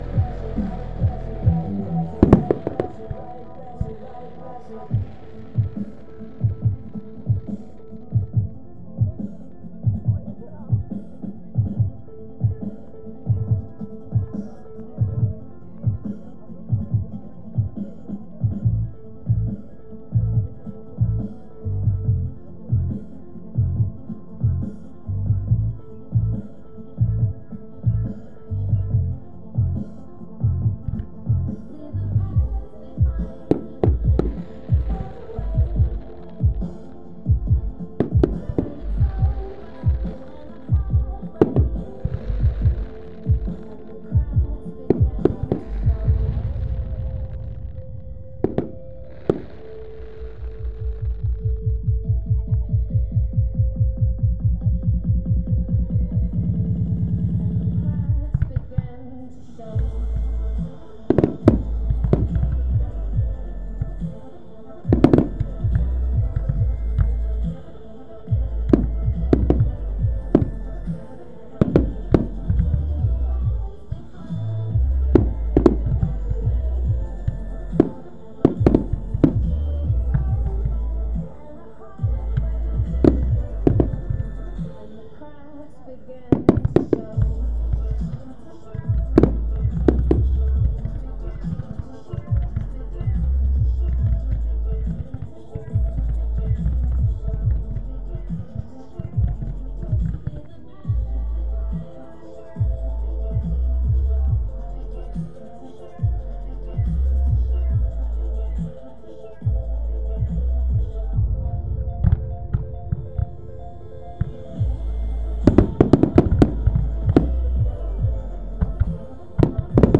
Firework Championship Team 1. End of display